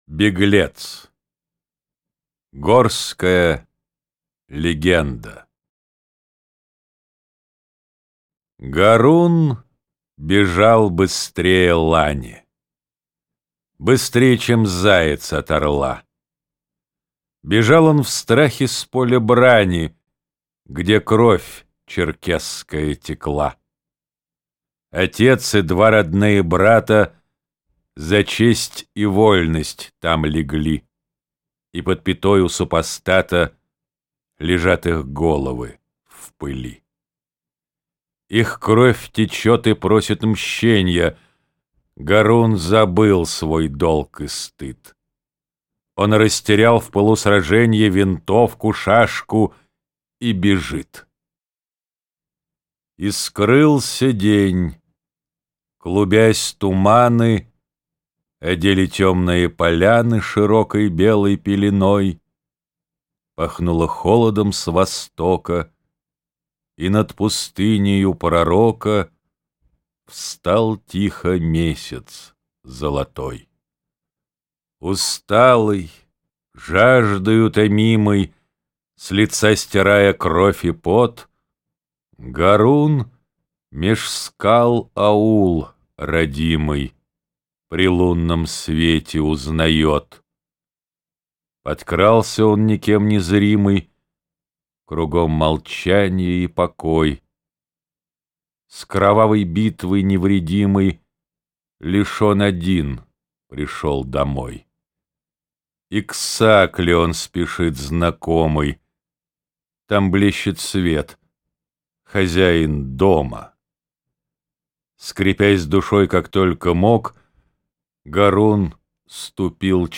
Аудиокнига Пророк. Стихи и поэмы | Библиотека аудиокниг